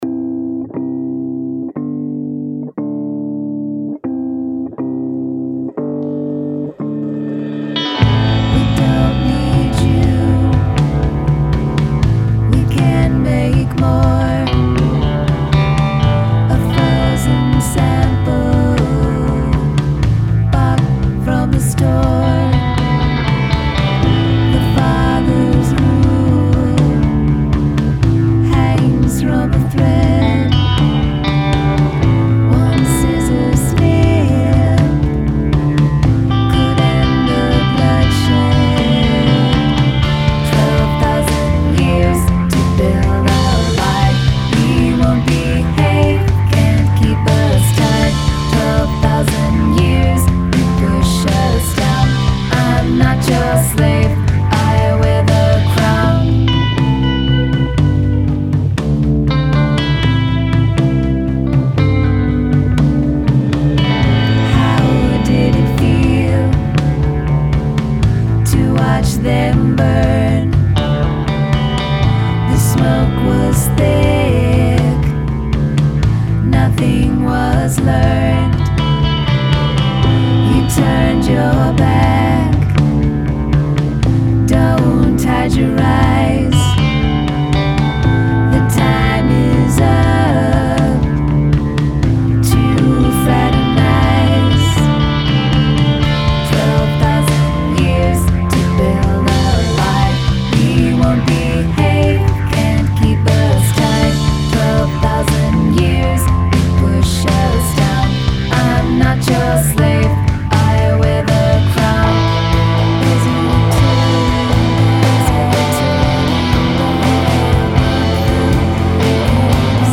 Diss Track
It's very ambient and compelling in how it's told.